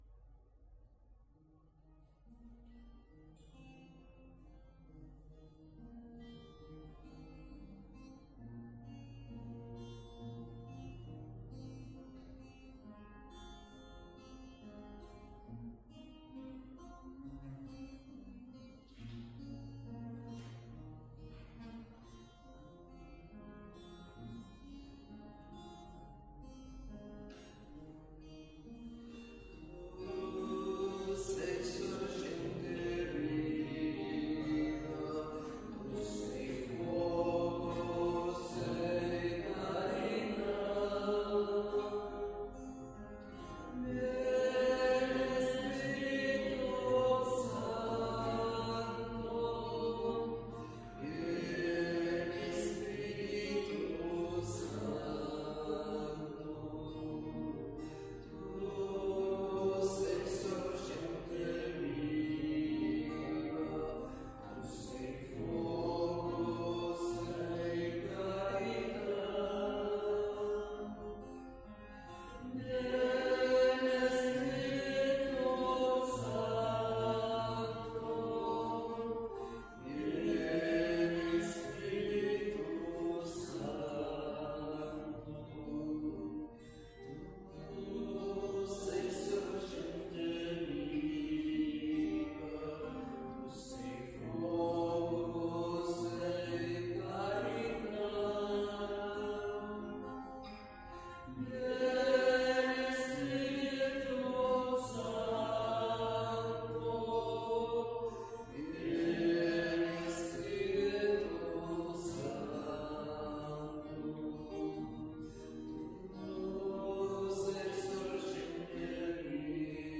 Església del Sagrat Cor - Diumenge 29 de juny de 2025
Vàrem cantar...